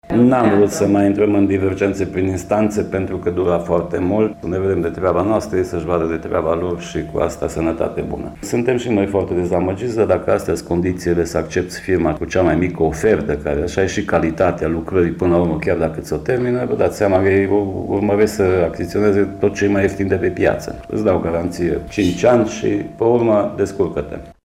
Revine primarul Laurențiu Boar: